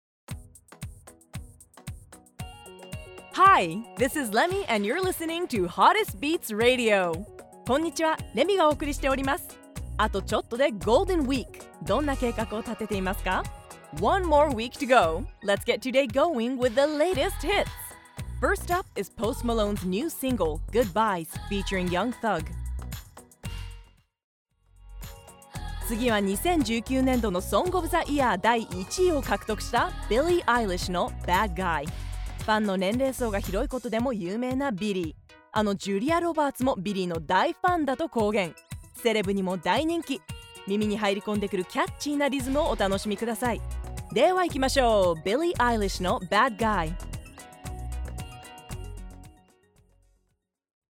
– ナレーション –
ラジオDJ（バイリンガル）